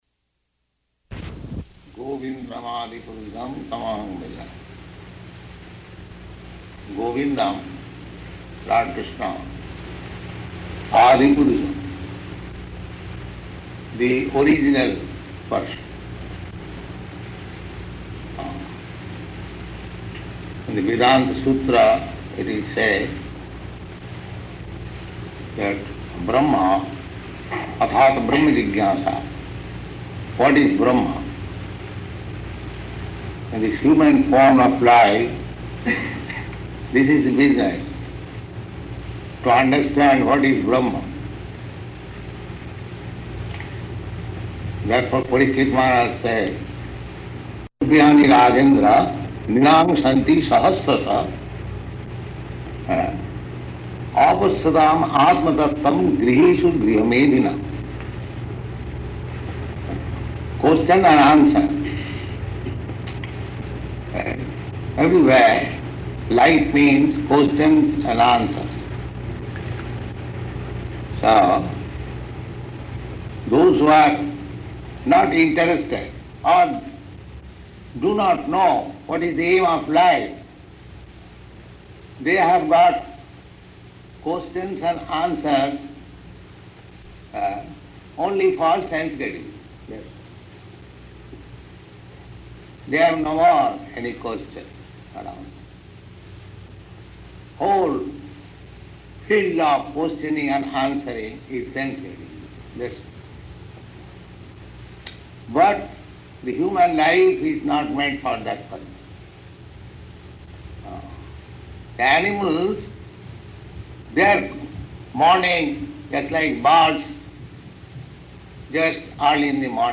Śrī Brahma-saṁhitā, Lecture
Śrī Brahma-saṁhitā, Lecture --:-- --:-- Type: Sri Brahma Samhita Dated: November 9th 1968 Location: Los Angeles Audio file: 681109BS-LOS_ANGELES.mp3 Prabhupāda: Govindam ādi-puruṣaṁ tam ahaṁ bhajāmi ** .